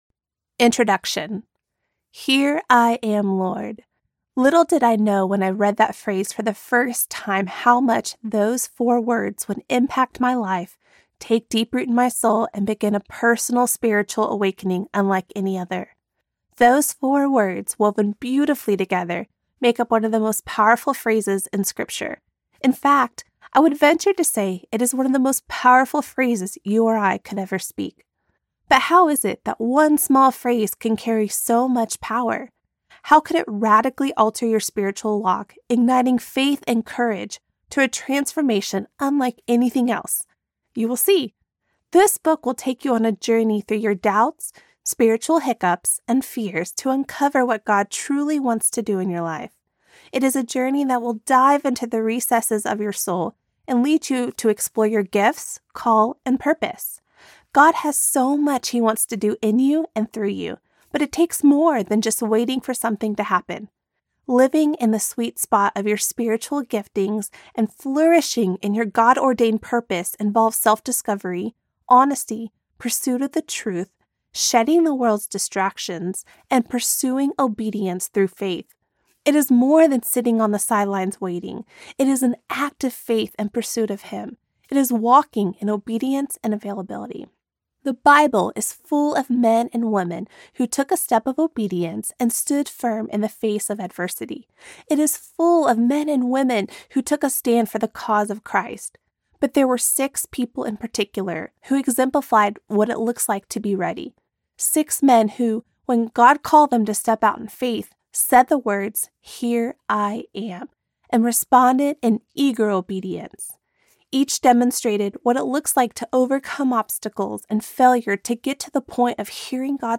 Here I Am Audiobook
Narrator